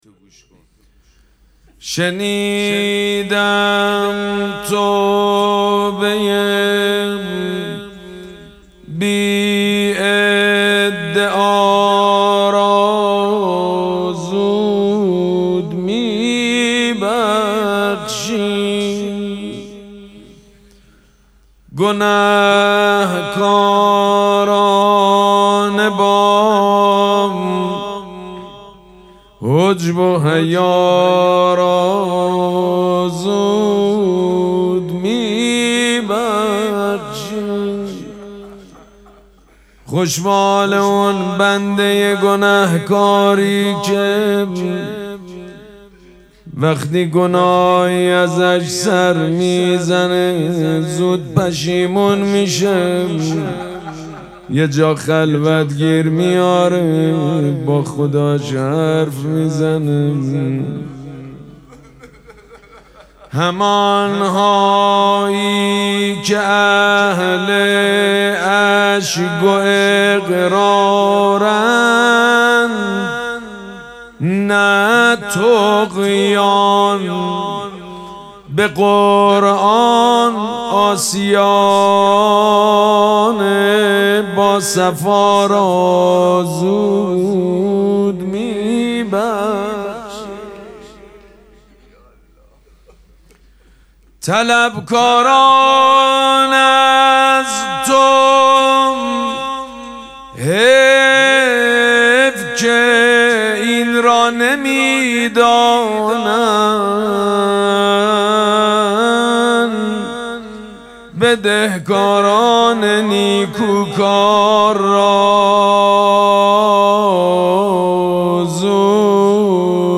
مراسم مناجات شب یازدهم ماه مبارک رمضان
حسینیه ریحانه الحسین سلام الله علیها
مناجات
حاج سید مجید بنی فاطمه